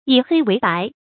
以黑为白 yǐ hēi wéi bái
以黑为白发音